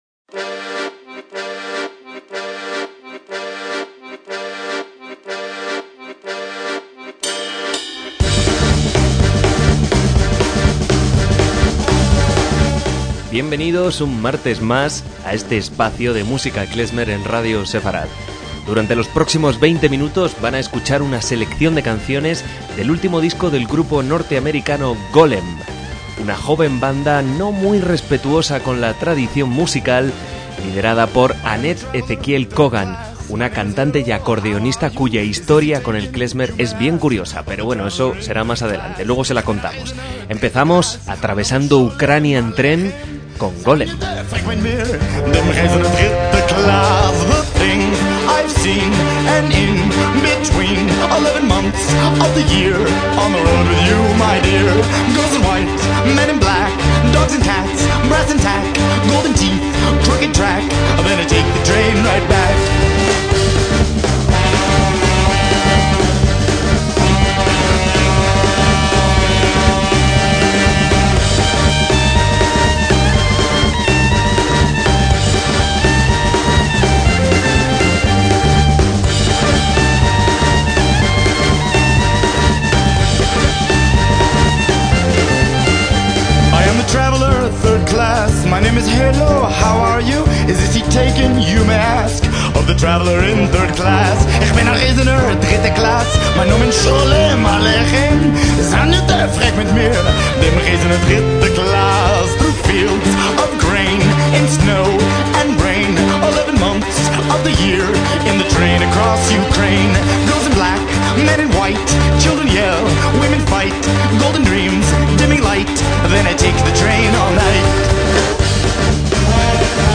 MÚSICA KLEZMER
banda de rock klezmer